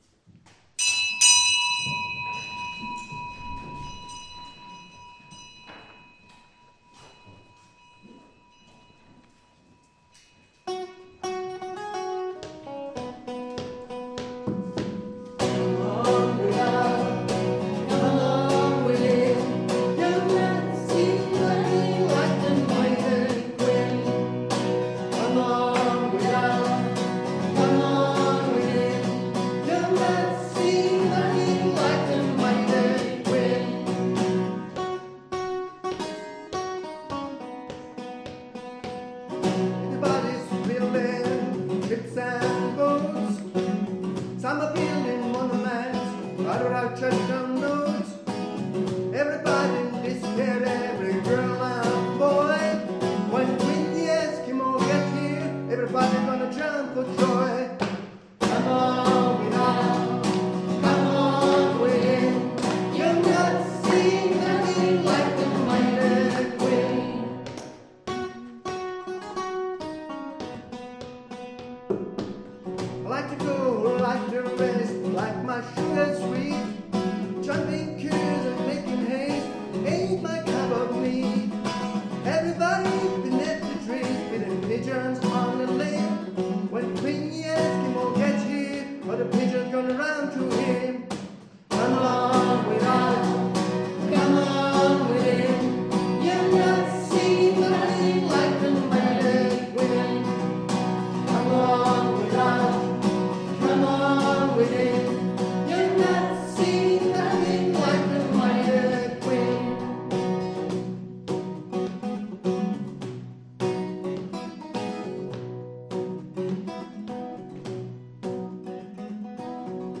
aus der Pfarrkirche Karnburg
Rockmesse im Mai 2 MB Gloria (Mighty Quinn)